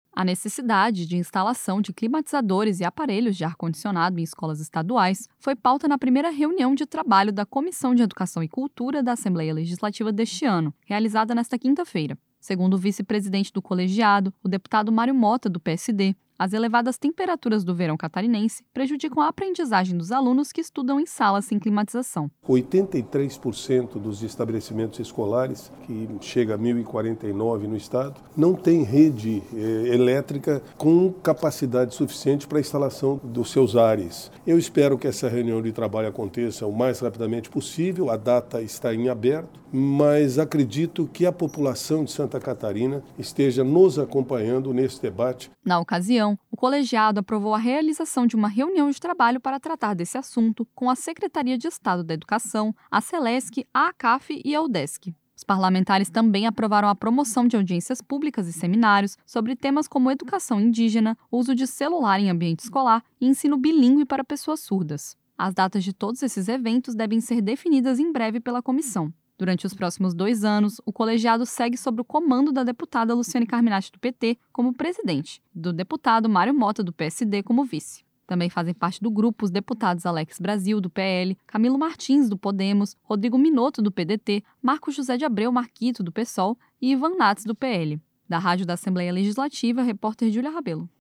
Entrevista com:
- deputado Mario Motta (PSD), vice-presidente da Comissão de Educação e Cultura da Alesc.